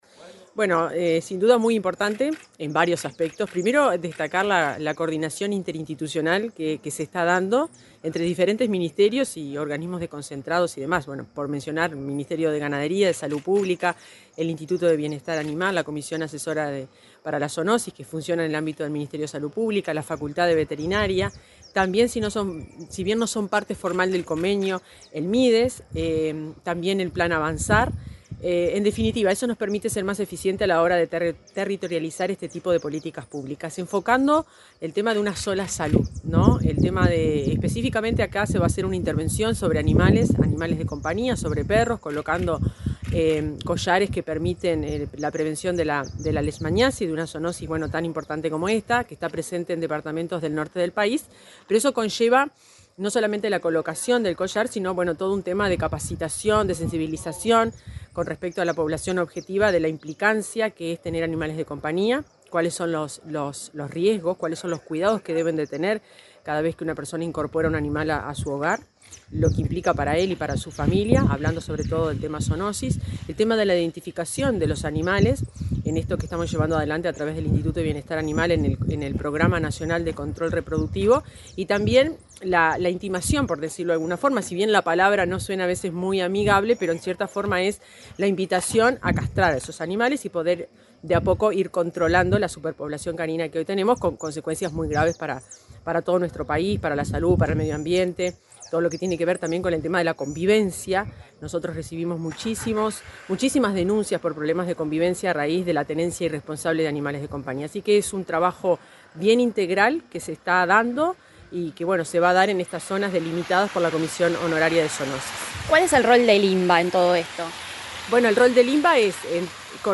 Entrevista a la directora general del MGAP, Fernanda Maldonado
El Ministerio de Ganadería, Agricultura y Pesca (MGAP), el Instituto Nacional de Bienestar Animal, la Comisión Nacional Honoraria de Zoonosis y la Fundación Marco Podestá firmaron un acuerdo en Salto, a fin de definir la estrategia para colocar más de 30.000 collares en canes a efectos de combatir la leishmaniasis. La directora general del MGAP, Fernanda Maldonado, dialogó con Comunicación Presidencial luego del acto.